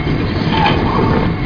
gearsmove.mp3